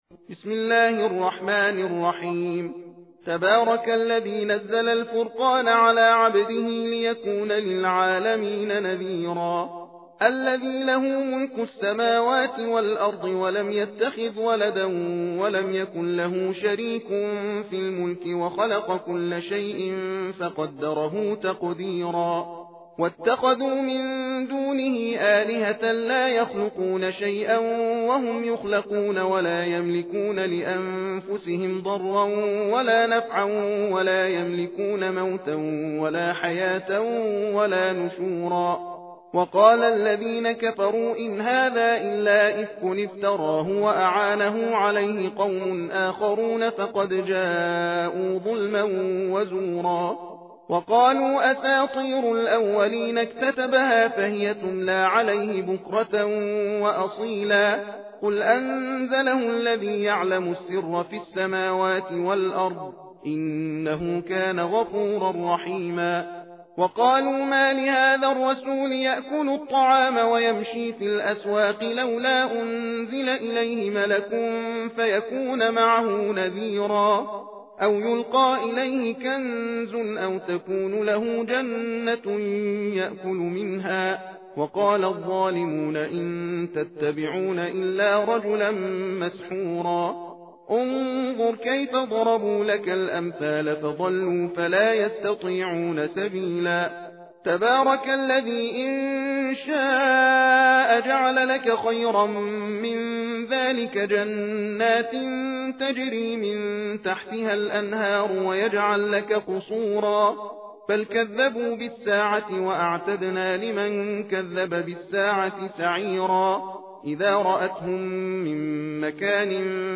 تحدیر (تندخوانی) سوره فرقان